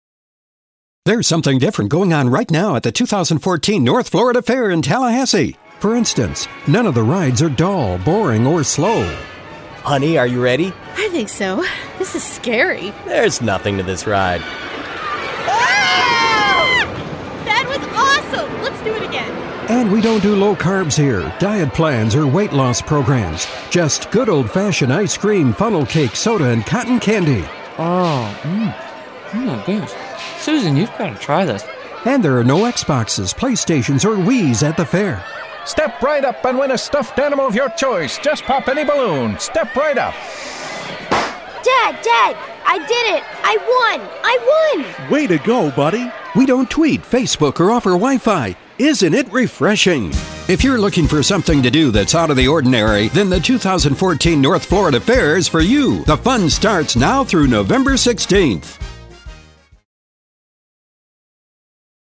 North Florida Fair Radio Ad
Multiple character audio with sound effects to capture the essence of the Fair.